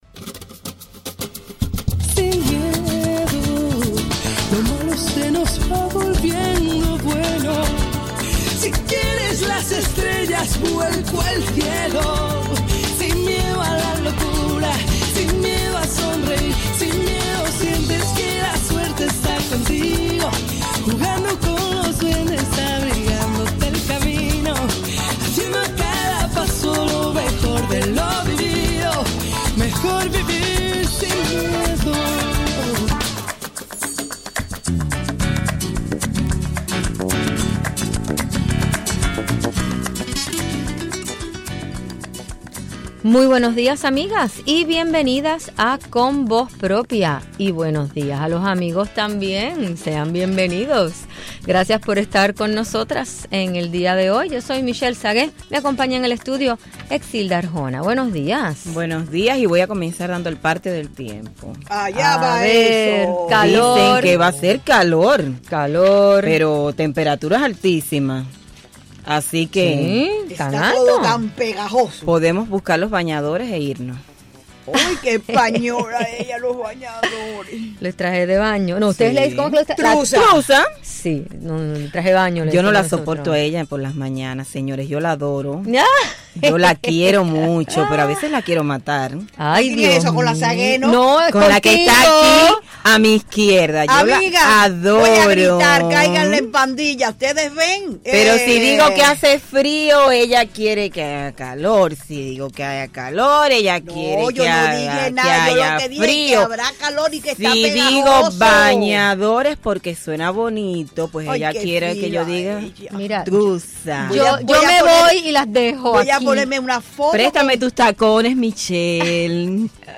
En nuestros estudios